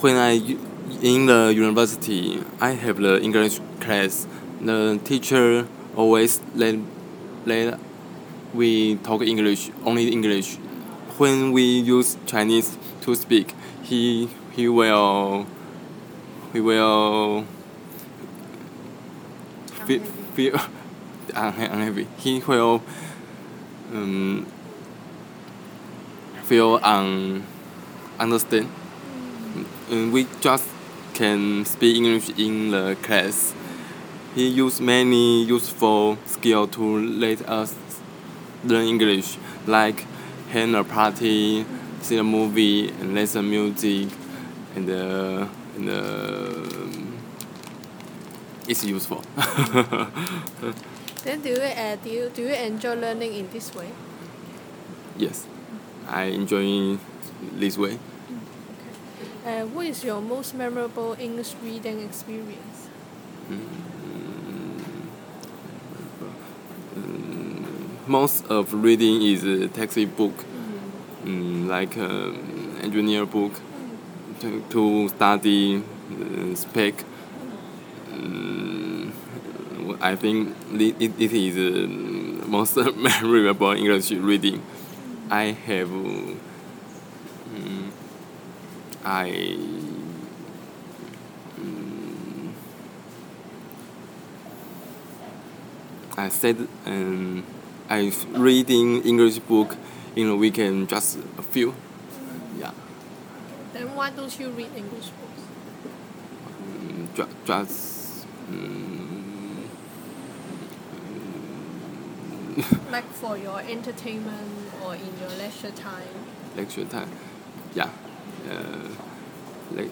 Subcategory: Audio/Visual, Non-fiction, Reading, Speech